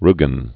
(rgən, rü-)